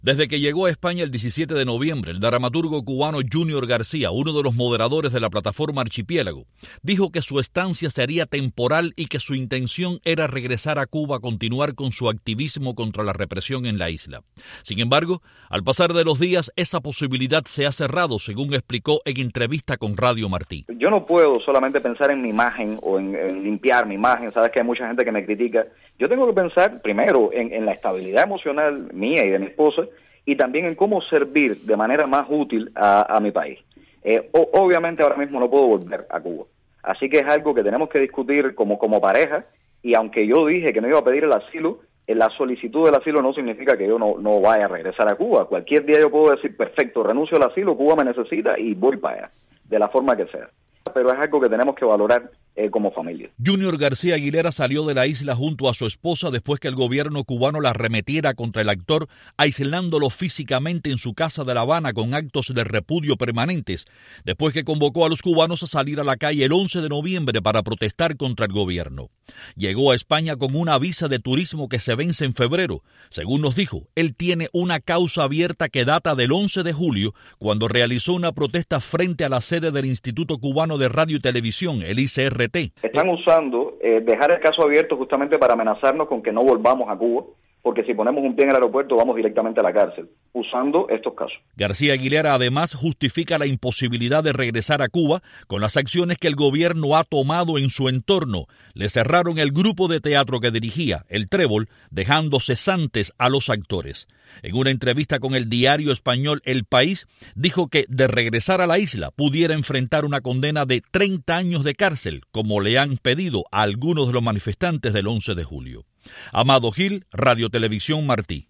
Yunior García Aguilera habla con Radio Martí sobre su intención de pedir asdilo político